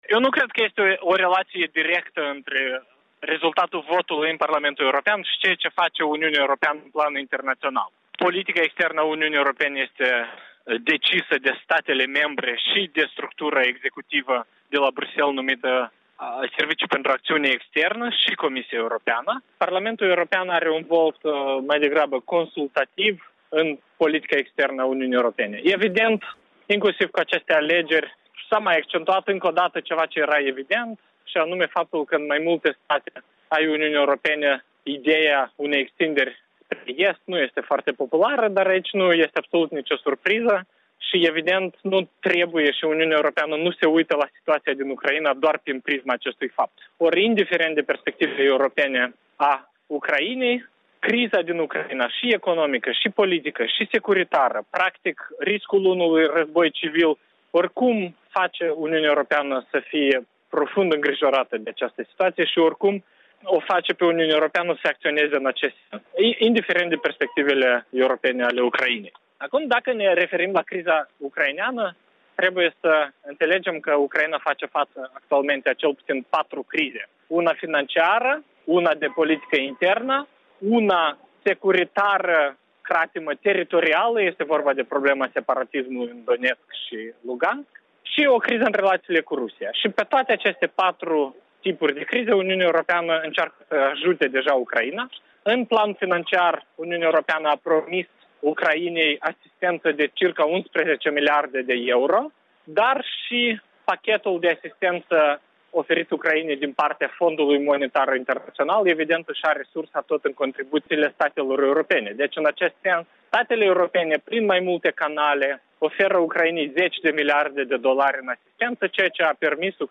Un interviu cu expertul Nicu Popescu